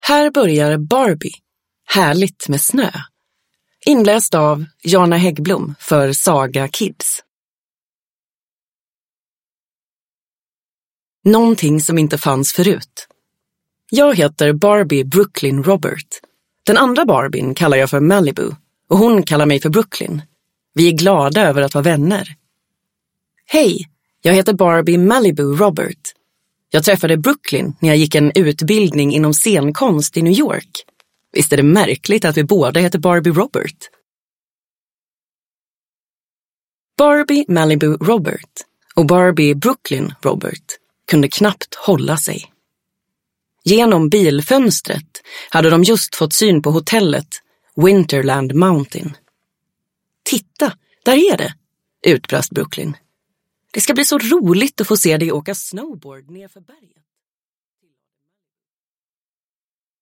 Barbie - Härligt med snö! (ljudbok) av Mattel